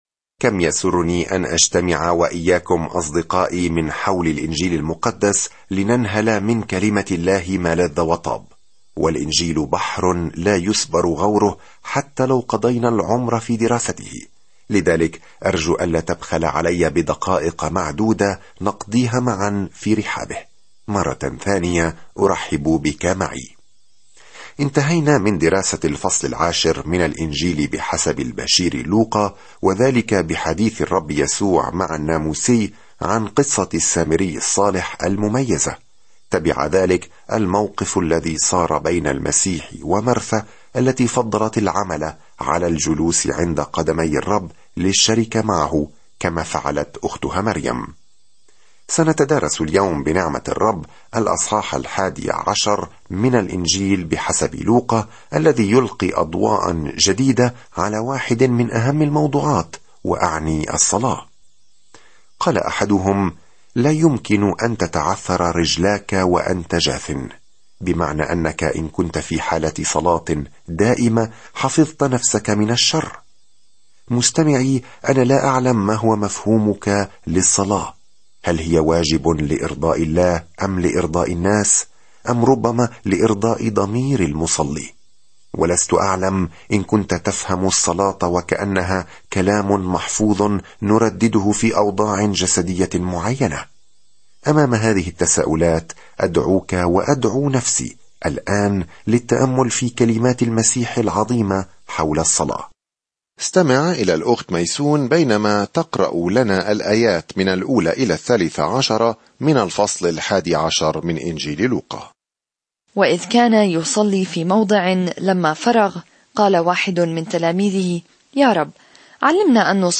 الكلمة لُوقَا 1:11-26 يوم 13 ابدأ هذه الخطة يوم 15 عن هذه الخطة تابع رحلتك عبر الكتاب المقدس بخير باستخدام خطة الدراسة الصوتية للوقا، التالية في الستار: ابدأ الآن!ينقل شهود العيان الأخبار السارة التي يرويها لوقا عن قصة يسوع منذ الولادة وحتى الموت وحتى القيامة؛ ويعيد لوقا أيضًا سرد تعاليمه التي غيرت العالم. سافر يوميًا عبر لوقا وأنت تستمع إلى الدراسة الصوتية وتقرأ آيات مختارة من كلمة الله.